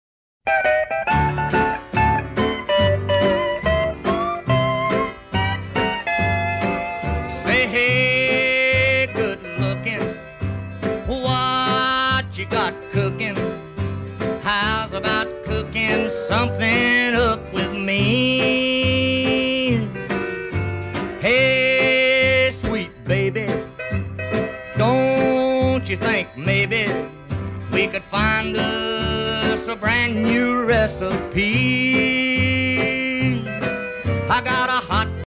High speed street racing.. don’t